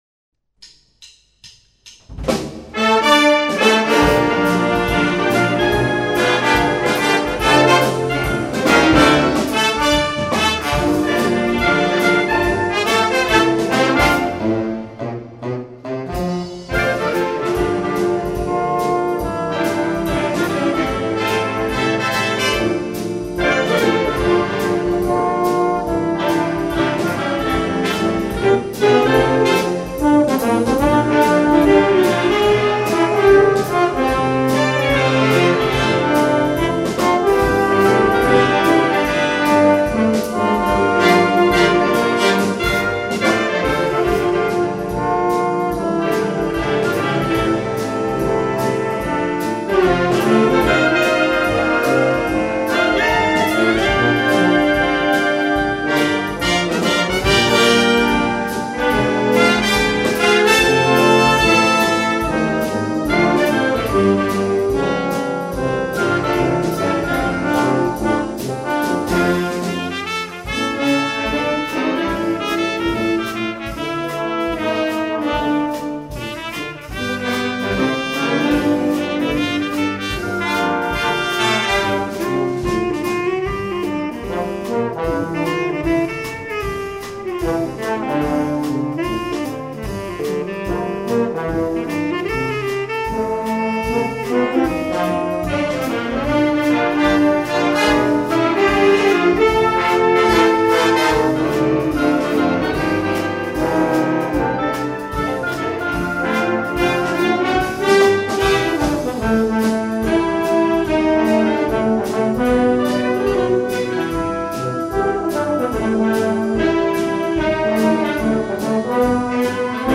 Swing per banda
Uno swing che trasforma la banda in una orchestra jazz!